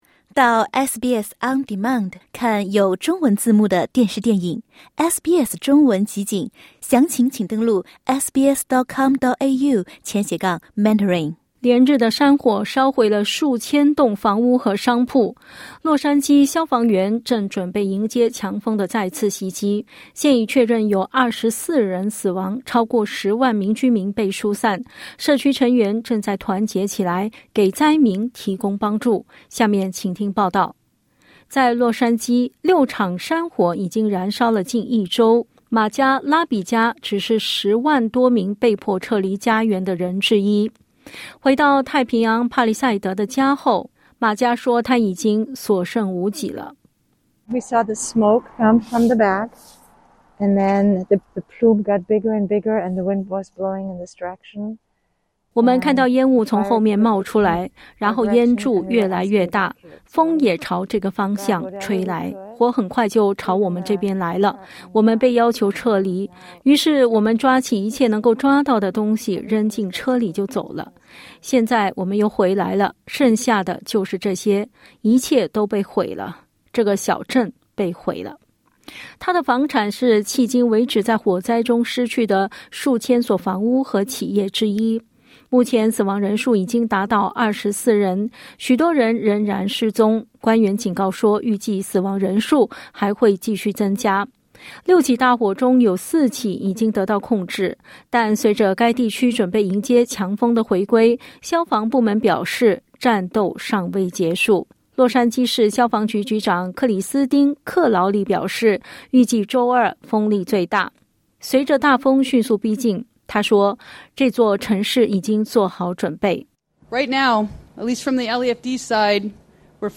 连日的山火烧毁了数千栋房屋和商铺，洛杉矶消防员正准备迎接强风的再次袭击。（点击音频收听详细报道）